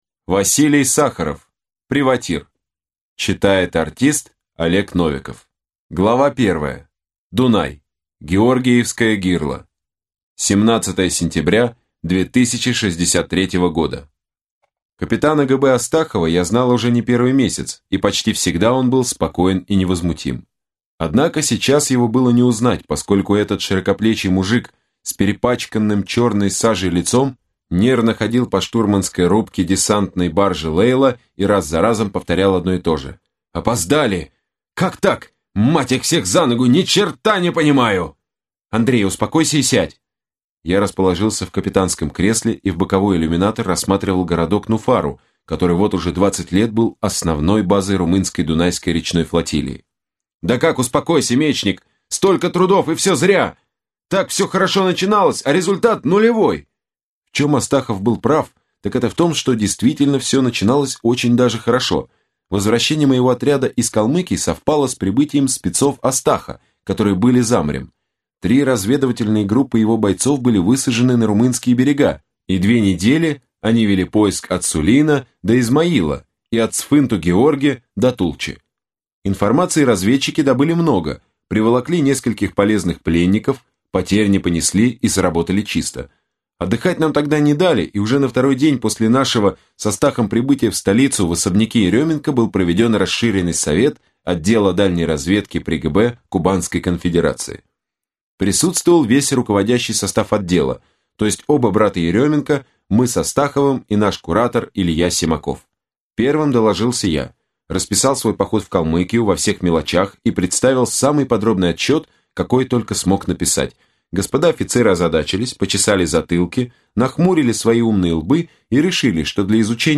Аудиокнига Приватир | Библиотека аудиокниг